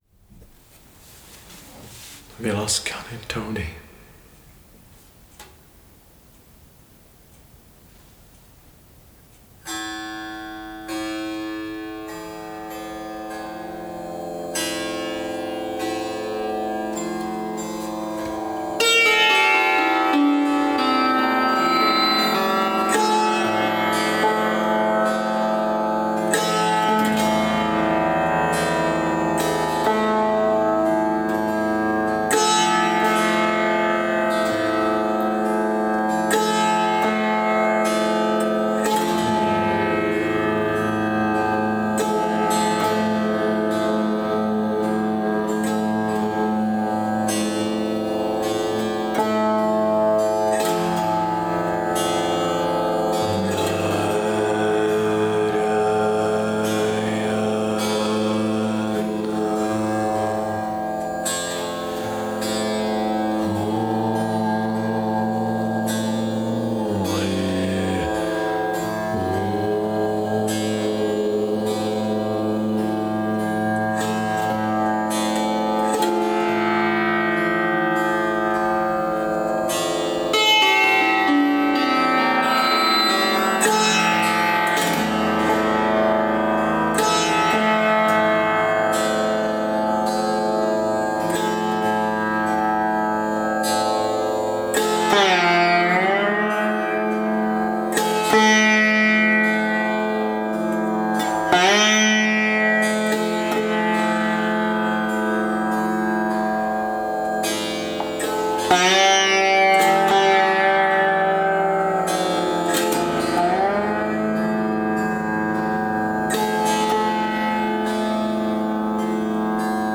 Indian Classical Music (i.e. traditional North Indian ragas).
The following audio tracks were captured during various Music Meditation sessions in Prague. A traditional raag was always presented first in the form of Alap-Jor-Jhalla, usually followed with a Kabir bhajan featuring tabla and vocals:
sitar+vocal+tabla